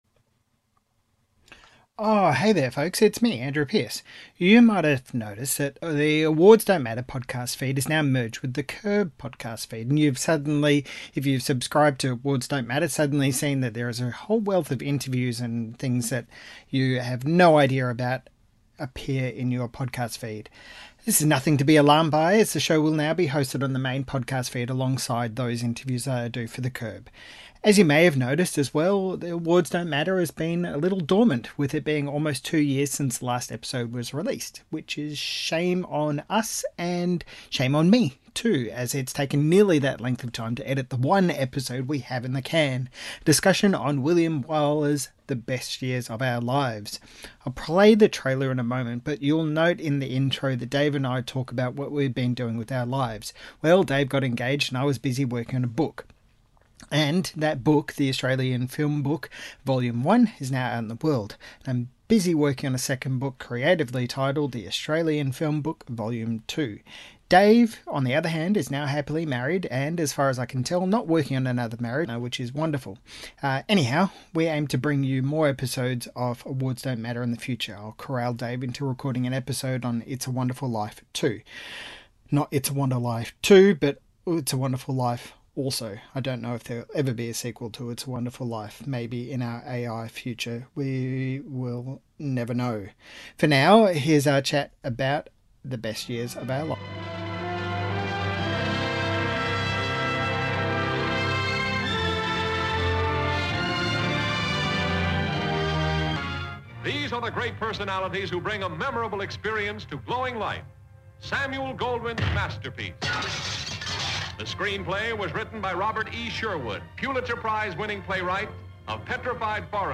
Listen to two dudes who chat about whether it’s good or not and ask whether The Best Years of Our Lives matters any more.